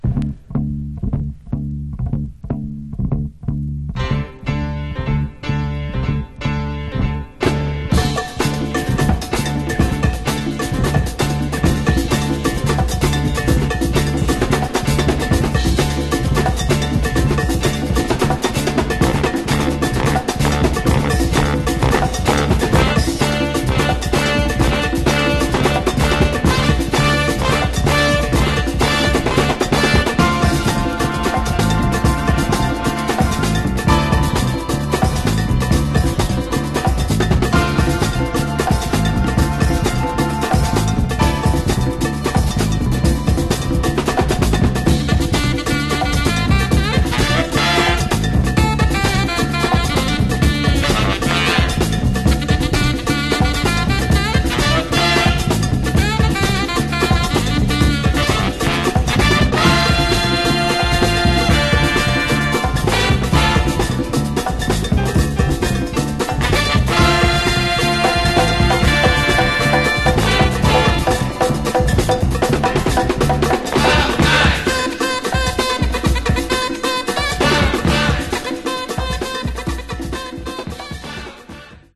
Genre: Latin Instrumentals
Talk about exciting dance music!